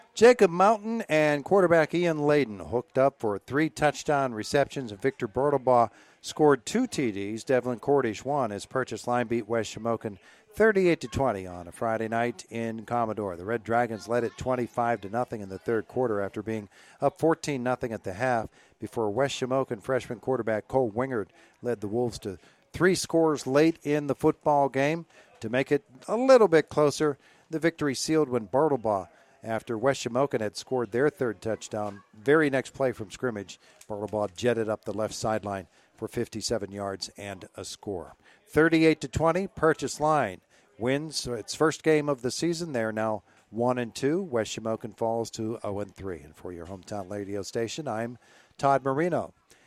ws-at-pl-recap.mp3